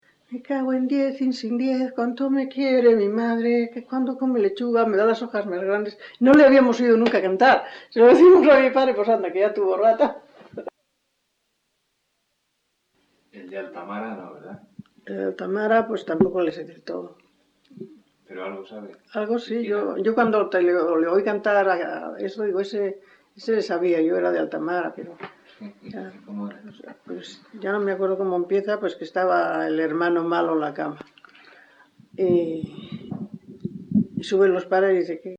Me cagüen diez y sin diez : [canto de trabajo]
Grabación realizada en La Overuela (Valladolid), en 1977.
Género / forma: Canciones populares-Valladolid (Provincia) Icono con lupa